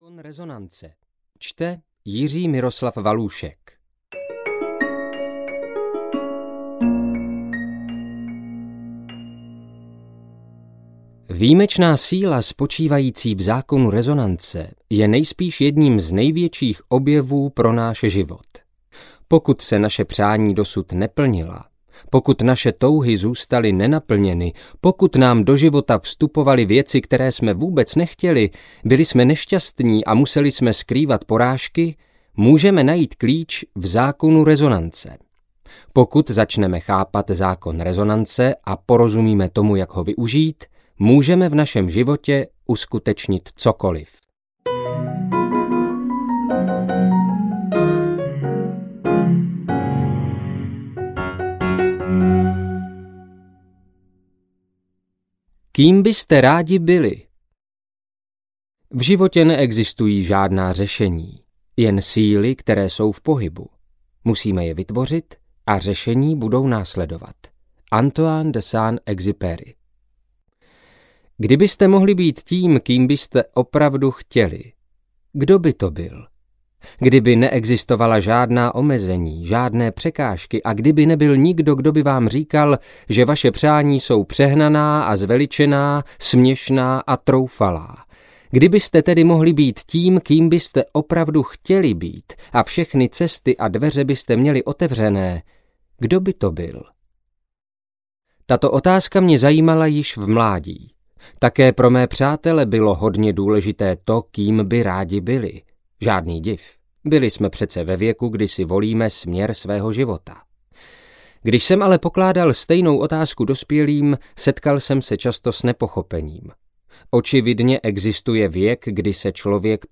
Zákon rezonance audiokniha
Ukázka z knihy